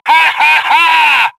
hahaha